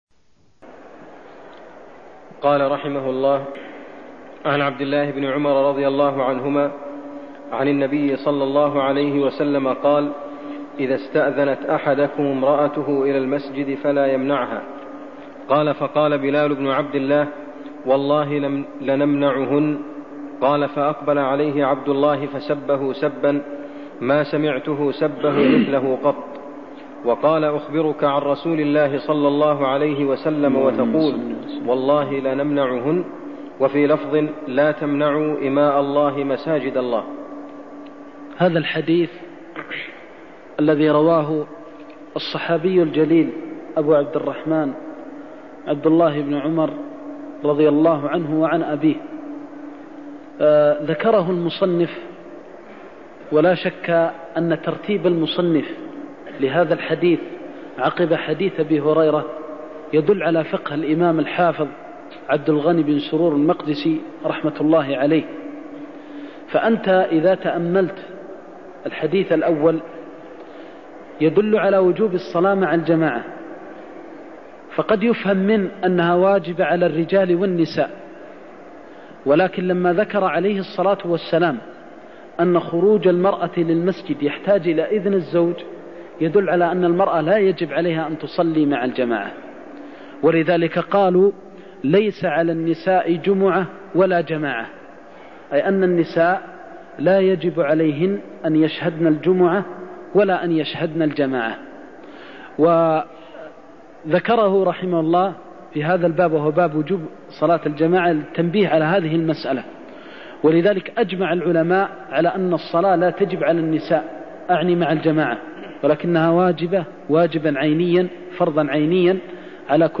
المكان: المسجد النبوي الشيخ: فضيلة الشيخ د. محمد بن محمد المختار فضيلة الشيخ د. محمد بن محمد المختار إذا استأذنت أحدكم امرأته إلى المسجد فلا يمنعها (57)